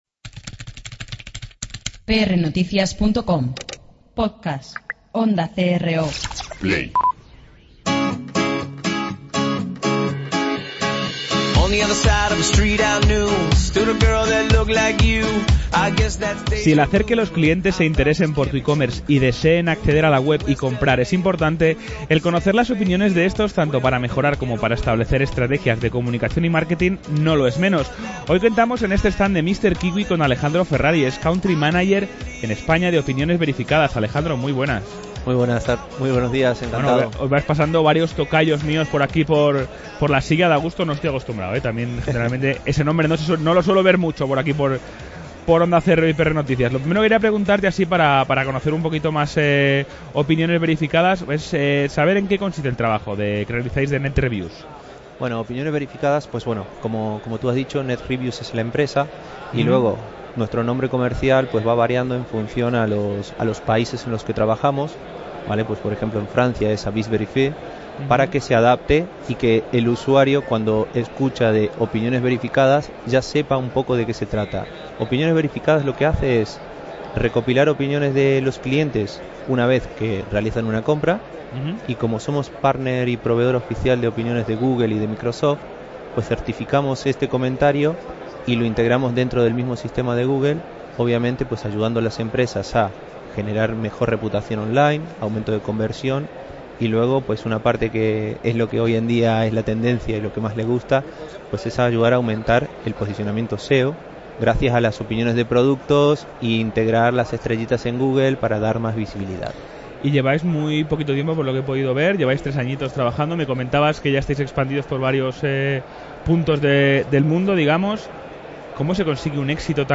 Para profundizar sobre el tema, hemos hablado en este stand de Mister Kiwi